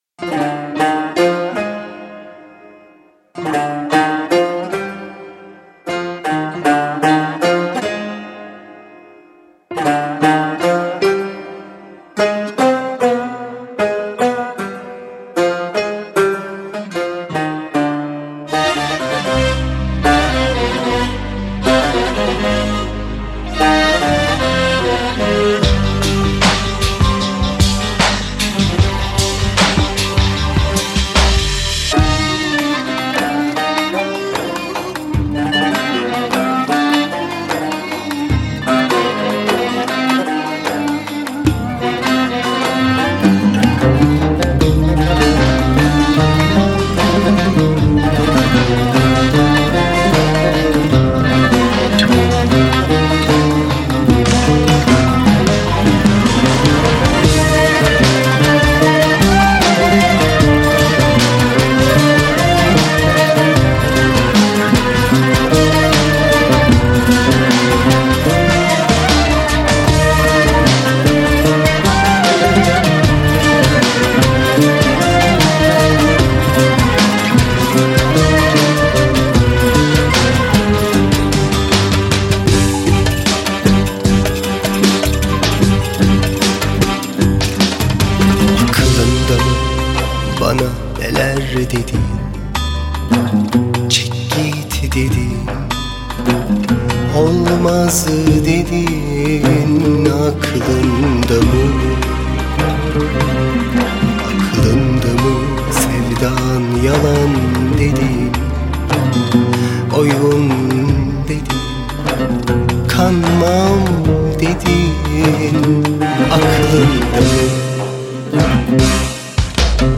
çift oktav hicaz
Pop Fantazi